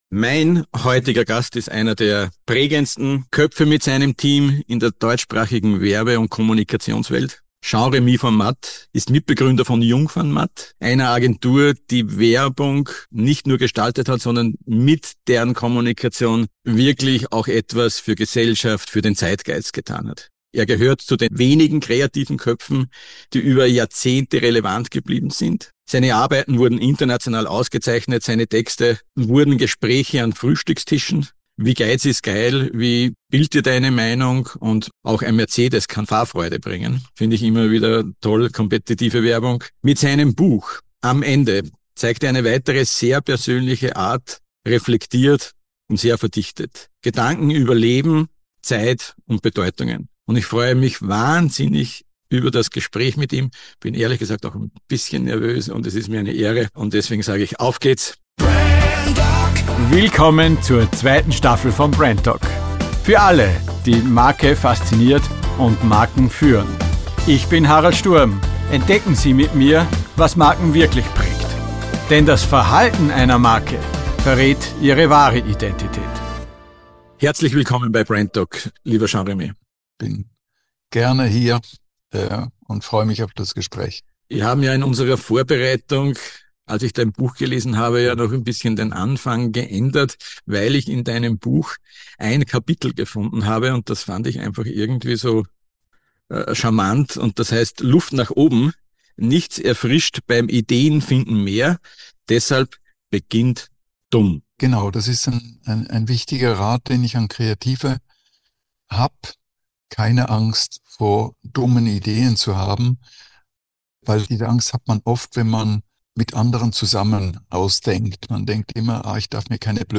Ein Gespräch über Mut, Wirkung und die Frage: Was passiert mit Marken, wenn man an der Idee spart? brandDOC – Der Podcast über Markenidentität und Markenverhalten Jetzt hören und abonnieren.